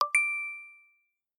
Apple Pay Sfx - Botão de Efeito Sonoro